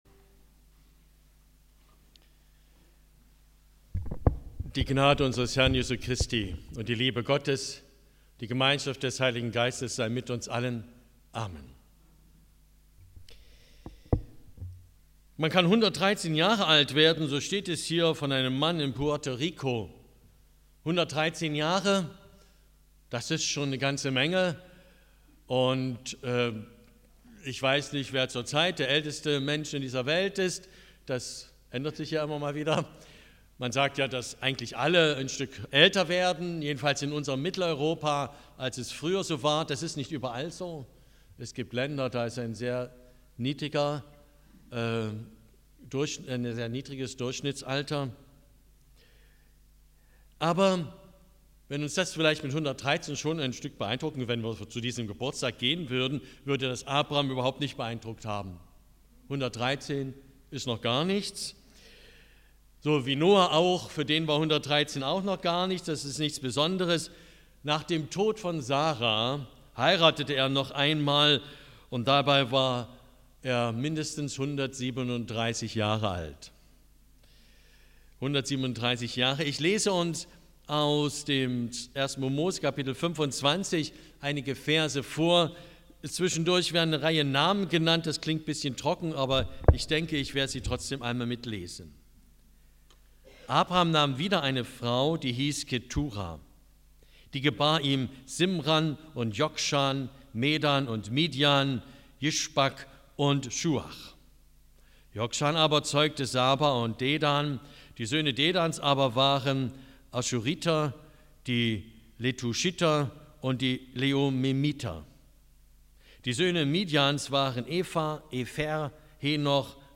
Predigt 23.10.2022